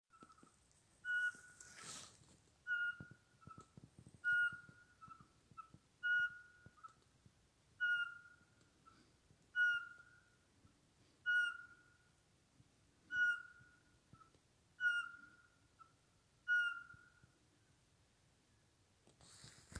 Птицы -> Совообразные ->
воробьиный сыч, Glaucidium passerinum
СтатусПоёт